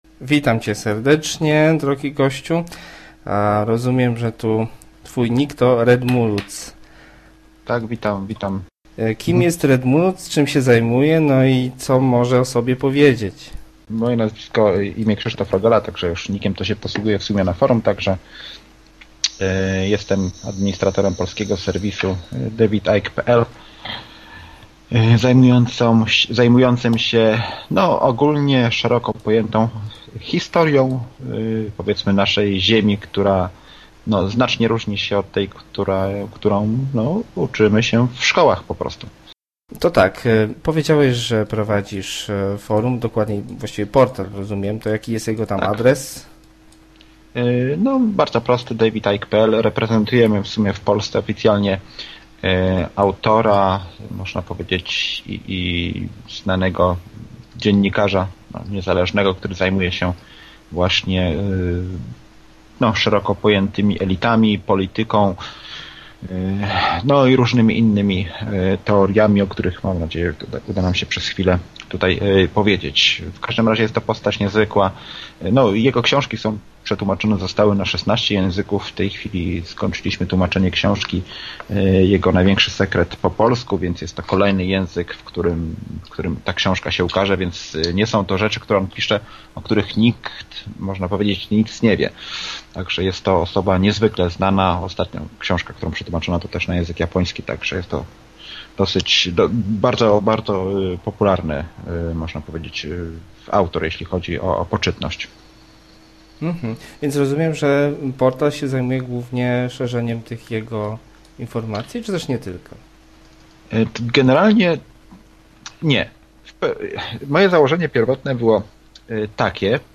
Wywiad
bez obróbki audio